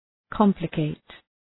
Προφορά
{‘kɒmplə,keıt} (Ρήμα) ● περιπλέκω ● μπερδεύω